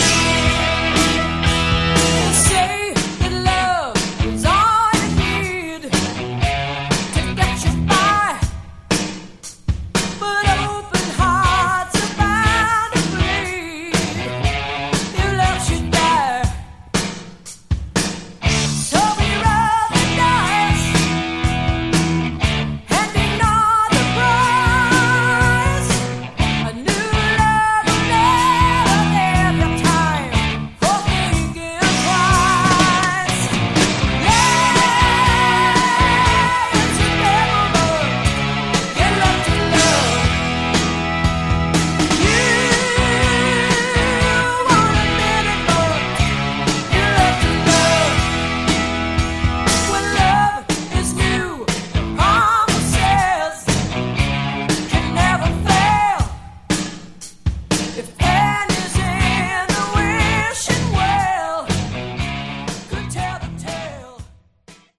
Category: AOR
lead vocals
guitars
bass
drums
keyboards